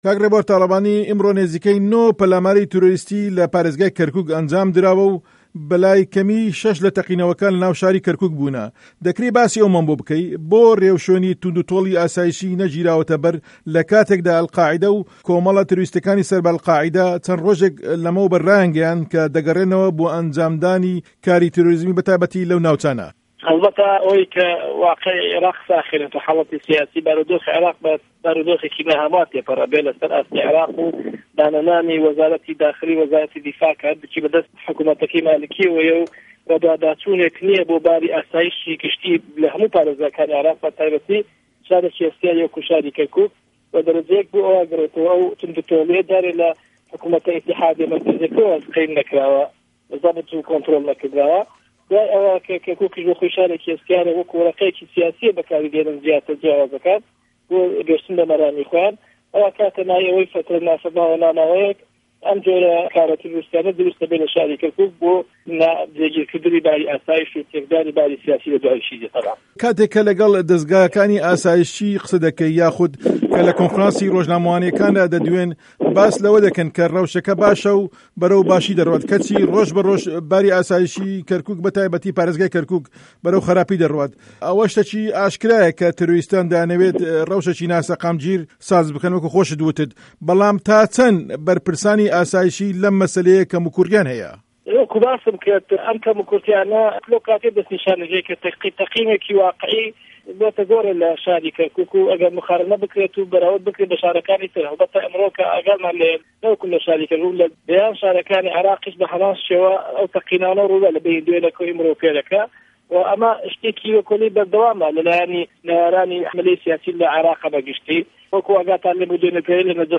گفتوگۆ له‌گه‌ڵ ڕێبوار تاڵه‌بانی 24 ی حه‌وتی 2012